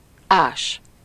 Ääntäminen
Synonyymit découper excaver investiguer Ääntäminen France: IPA: [kʁø.ze] Haettu sana löytyi näillä lähdekielillä: ranska Käännös Ääninäyte 1. ás Määritelmät Verbit Faire un trou , un orifice .